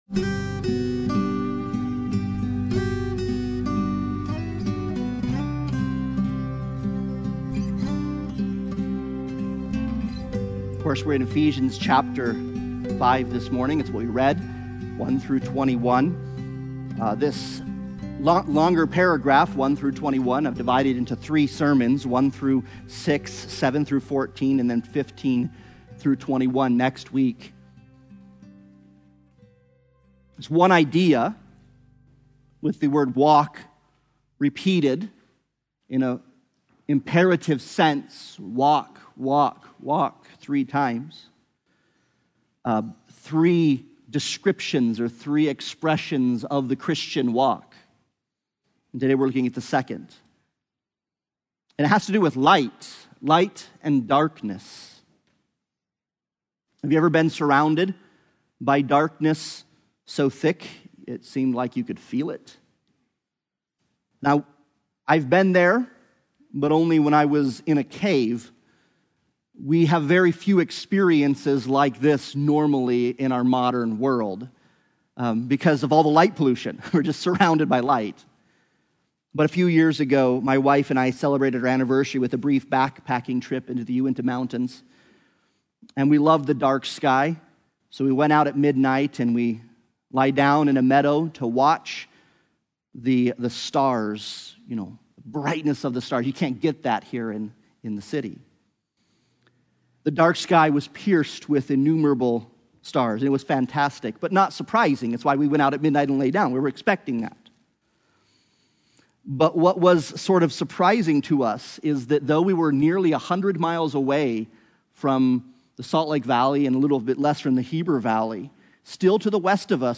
Life in Christ Service Type: Sunday Morning Worship « The Gospel According to Mark Walk Wisely